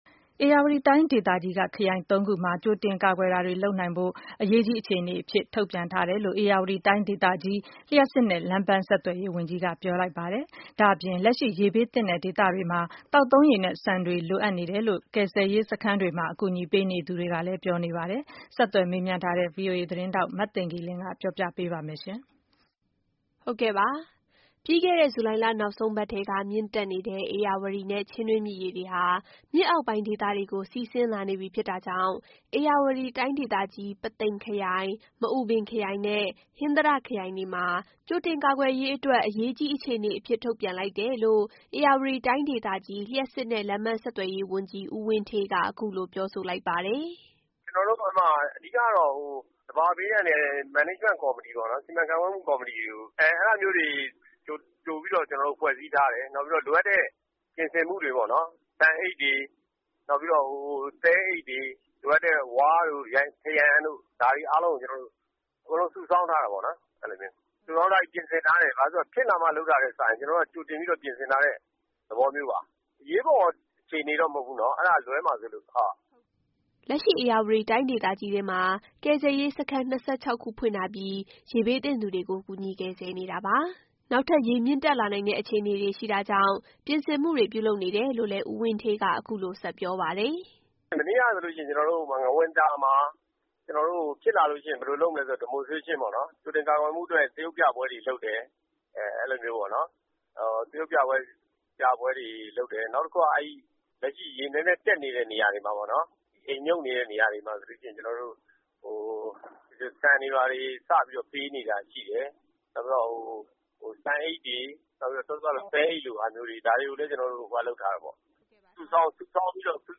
ပြီးခဲ့တဲ့ ဇူလိုင်လနောက်ဆုံးပတ်ထဲက မြင့်တက်နေတဲ့ ဧရာဝတီနဲ့ ချင်းတွင်းမြစ်ရေတွေဟာ မြစ်အောက်ပိုင်းဒေသတွေကို စီးဆင်း လာနေပြီဖြစ် တာကြောင့် ဧရာဝတီတိုင်းဒေသကြီးက ပုသိမ်ခရိုင်၊ မအူပင်ခရိုင်နဲ့ ဟင်္သာတ ခရိုင်တွေမှာ ကြိုတင်ကာကွယ်ရေးအတွက် အရေးကြီးအခြေအနေအဖြစ် ထုတ်ပြန် လိုက်တယ်လို့ ဧရာဝတီတိုင်းဒေသကြီး လျှပ်စစ်နဲ့လမ်းပမ်း ဆက်သွယ်ရေးဝန်ကြီး ဦးဝင်းဌေးက အခုလို ပြောဆိုလိုက်ပါတယ်။